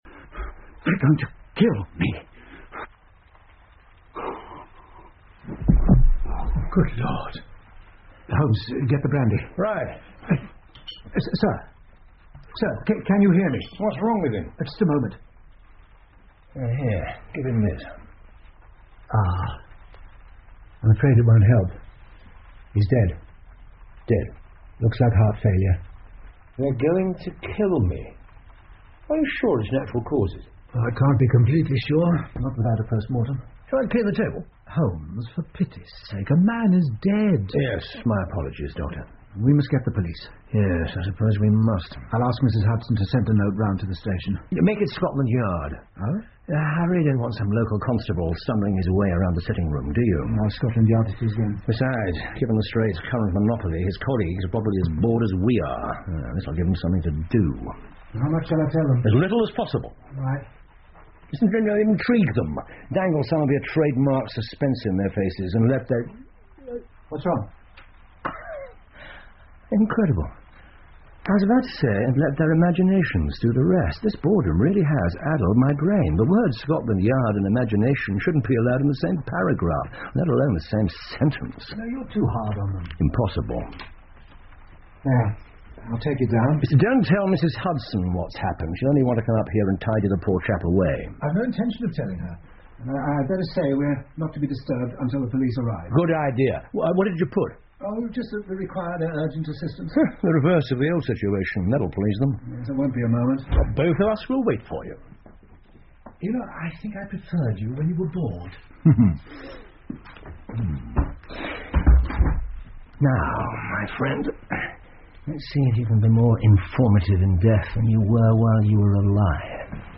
福尔摩斯广播剧 The Abergavenny Murder 2 听力文件下载—在线英语听力室